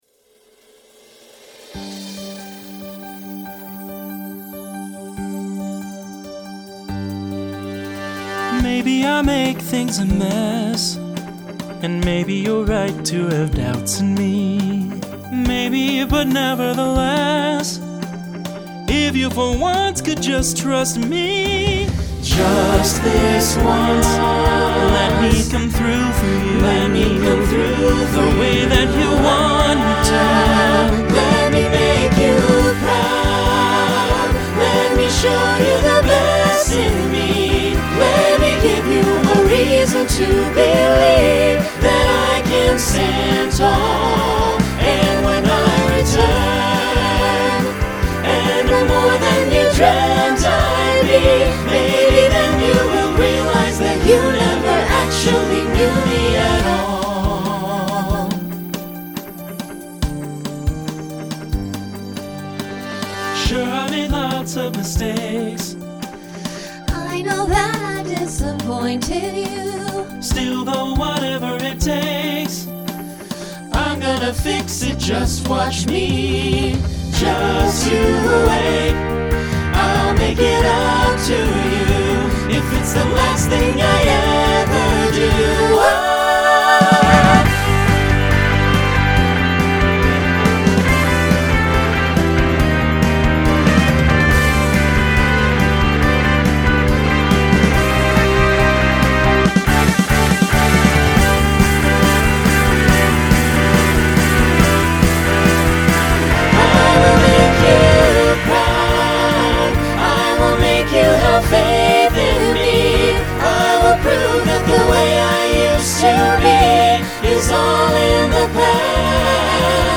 Genre Broadway/Film Instrumental combo
Solo Feature Voicing SATB